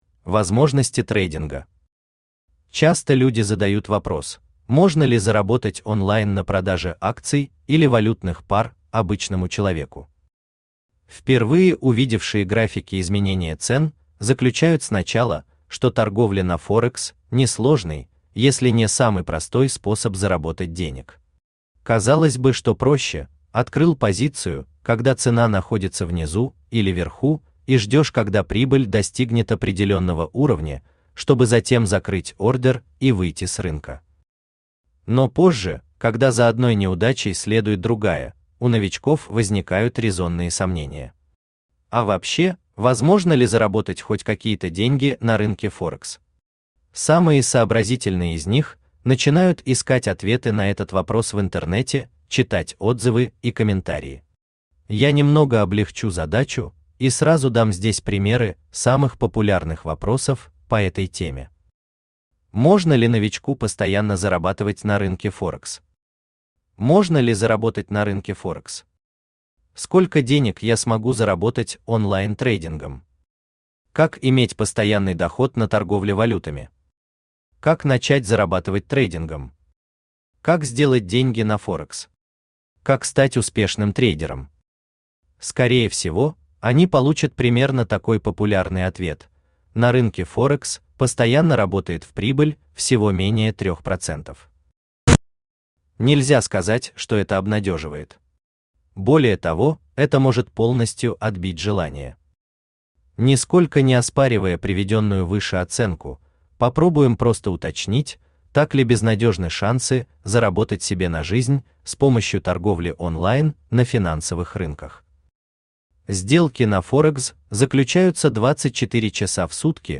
Аудиокнига Записки Форекс трейдера | Библиотека аудиокниг
Aудиокнига Записки Форекс трейдера Автор Александр Горбунов Читает аудиокнигу Авточтец ЛитРес.